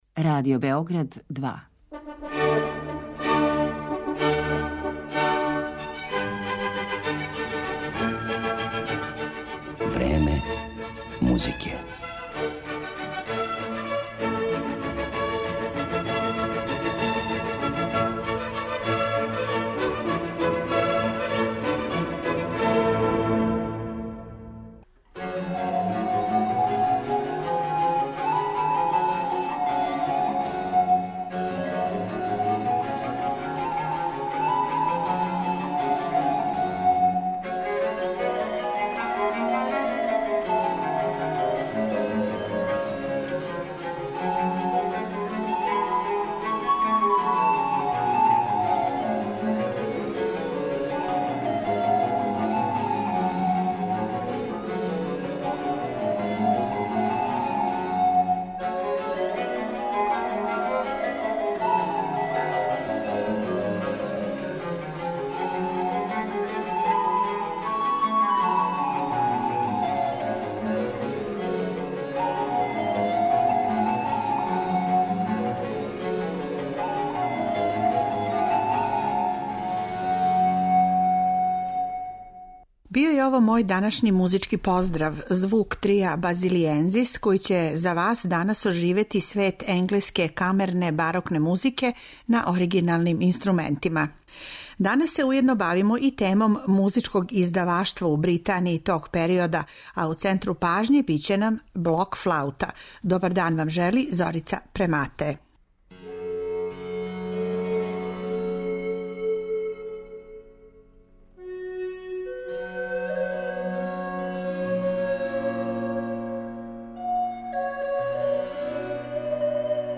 блок-флаута.